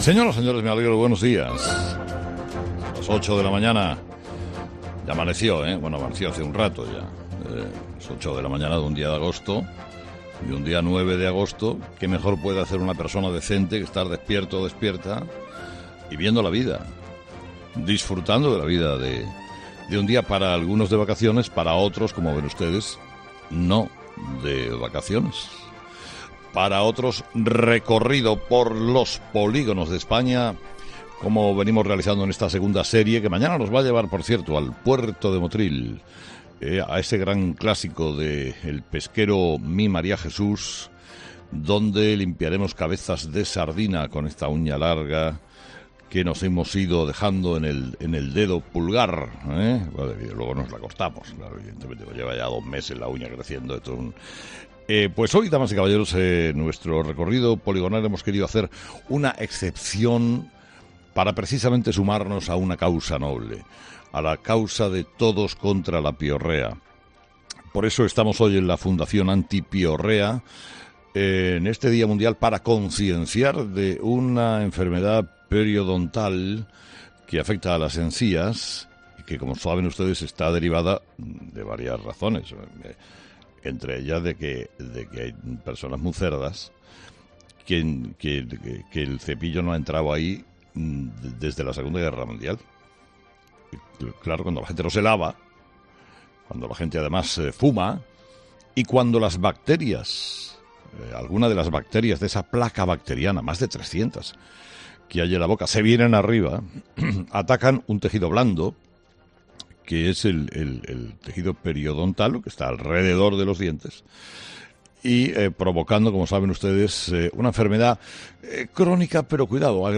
Monólogo de Carlos Herrera